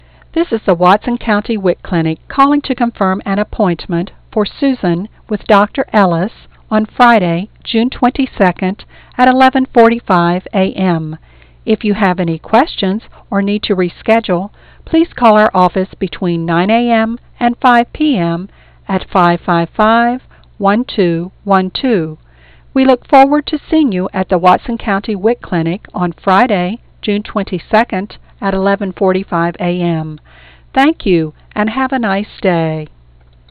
And you'll deliver your messages with professionally recorded, crystal-clear 16 bit sound.